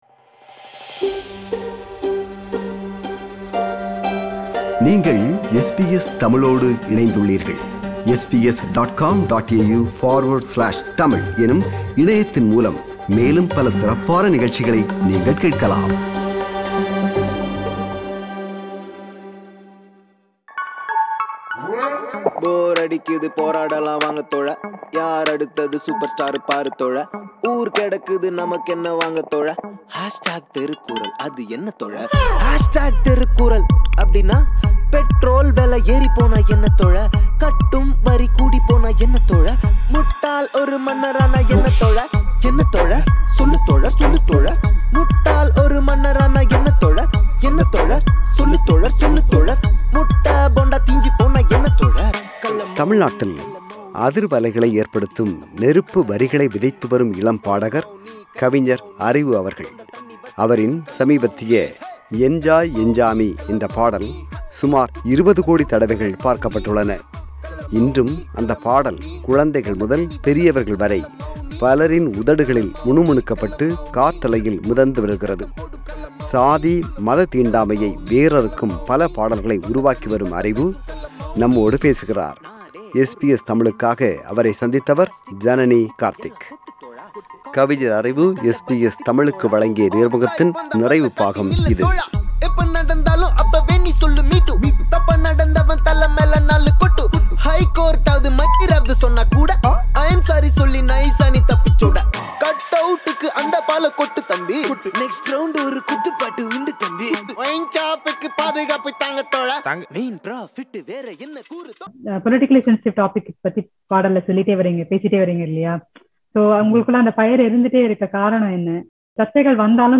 சாதி, மத தீண்டாமையை வேரறுக்கும் பல பாடல்களை உருவாக்கிவரும் அறிவு அவர்கள் நம்மோடு பேசுகிறார்.